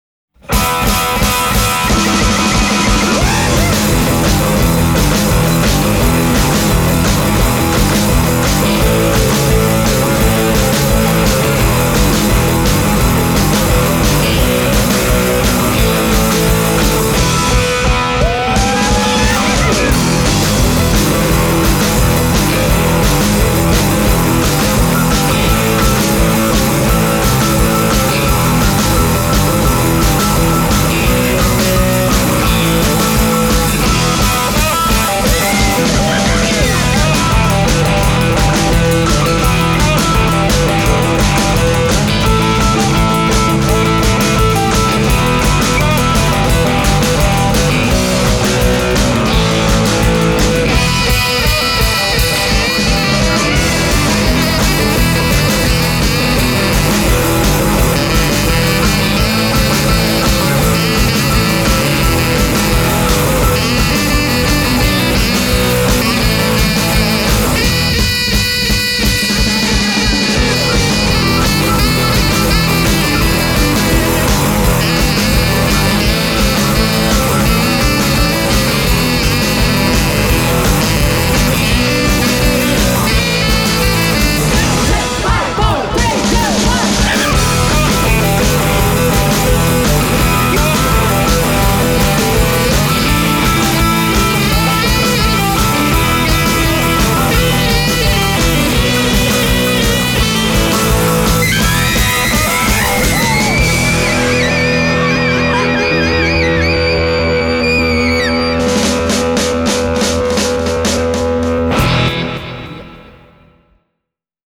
an American instrumental rock band
Genre: Instrumental